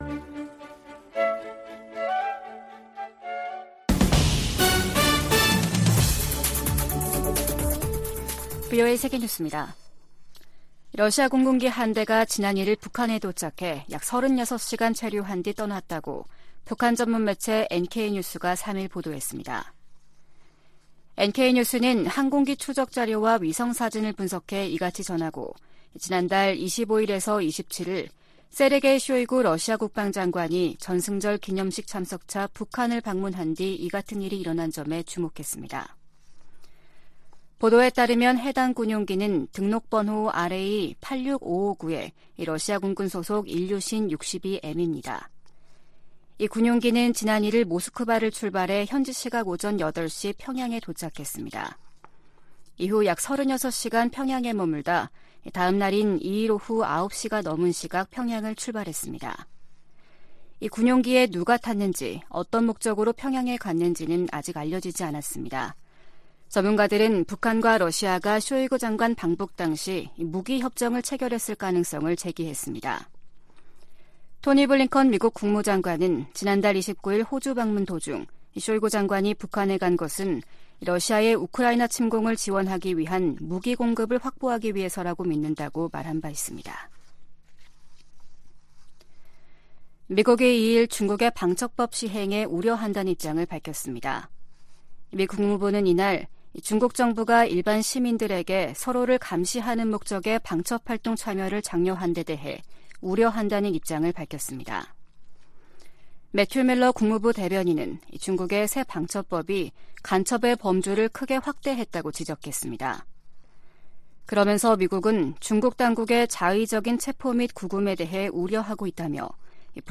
VOA 한국어 아침 뉴스 프로그램 '워싱턴 뉴스 광장' 2023년 8월 4일 방송입니다. 북한이 무단 월북 미군 병사 사건과 관련해 유엔군사령부에 전화를 걸어왔지만 실질적인 진전은 아니라고 국무부가 밝혔습니다. 핵확산금지조약(NPT) 당사국들이 유일하게 일방적으로 조약을 탈퇴한 북한을 비판했습니다. 김영호 한국 통일부 장관이 현 정부에서 종전선언을 추진하지 않을 것이라고 밝혔습니다.